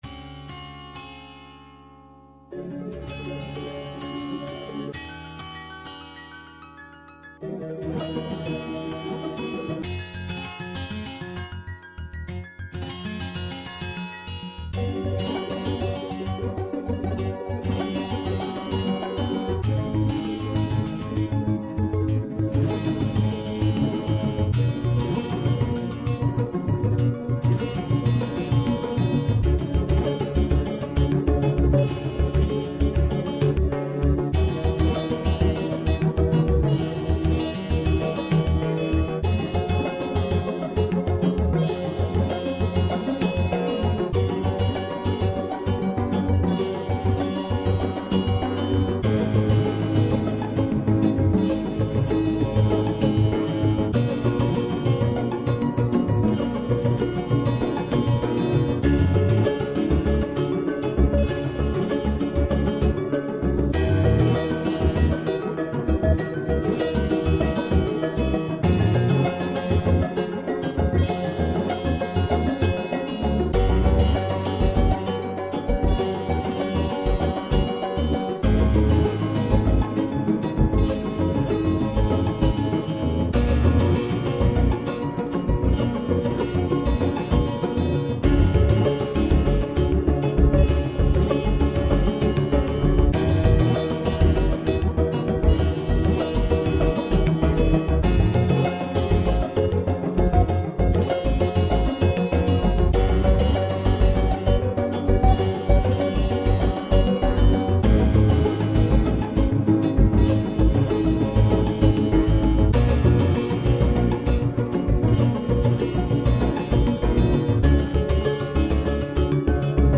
Some more selfmade music...
and is with also cello, harp, piano, a little bass and no drum.